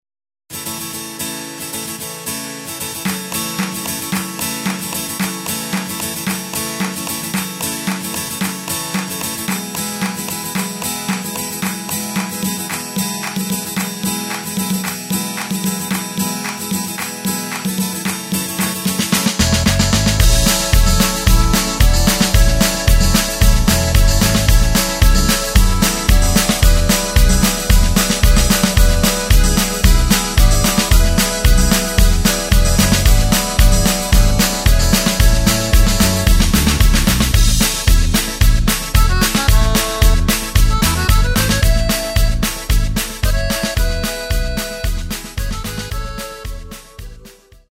Takt:          2/4
Tempo:         112.00
Tonart:            A
Schweizer Schlager aus dem Jahr 2025!
Playback mp3 Demo